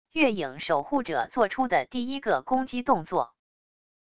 月影守护者做出的第一个攻击动作wav音频